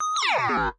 合成游戏音效 " 跳跃
描述：这是我为一个小游戏项目创建的声音包的一部分。用LMMS里面的ZynAddSubFX创建。
标签： 合成 LMMS 游戏 zynaddsubfx SFX 科幻
声道立体声